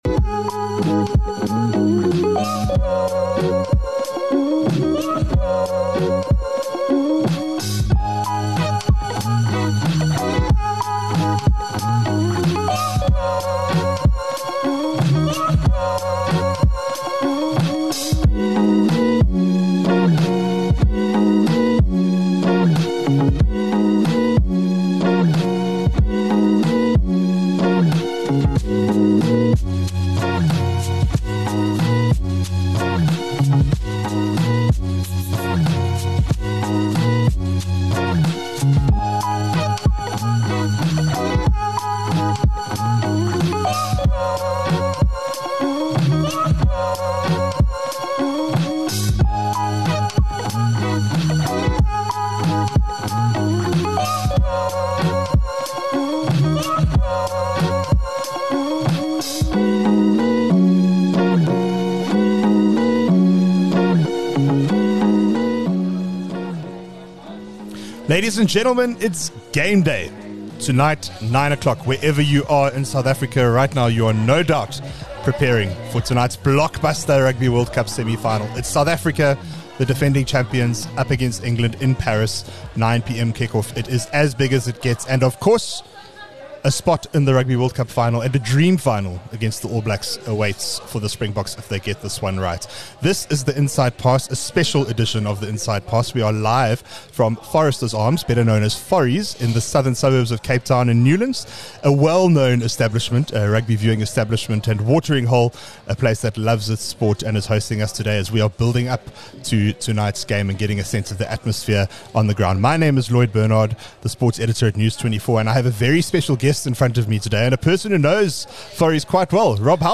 21 Oct The Inside Pass: LIVE from Foresters Arms - Feel it!